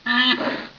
c_rhino_hit3.wav